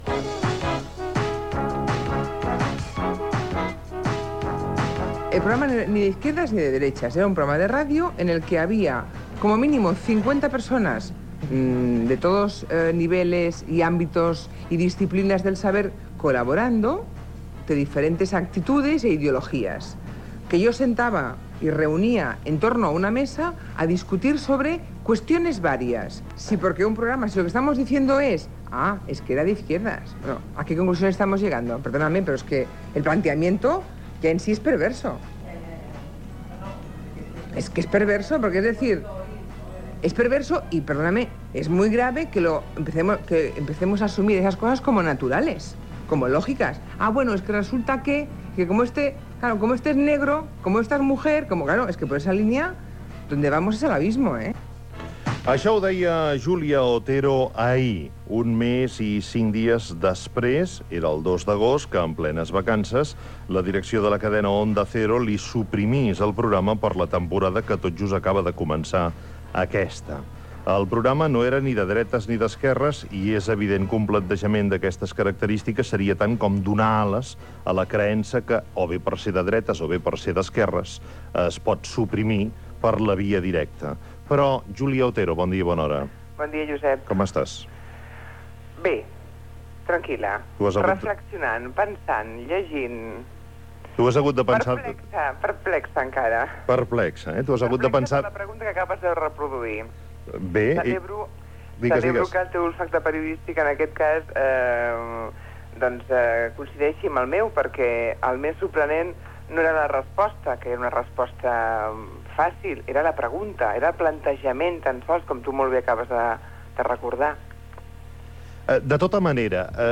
Entrevista telefònica a la presentadora Julia Otero, després del seu acomiadament de la cadena Onda Cero Radio quan a l'estiu d'aquell any li van suprimir el programa
Info-entreteniment